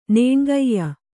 ♪ nēṇgaiya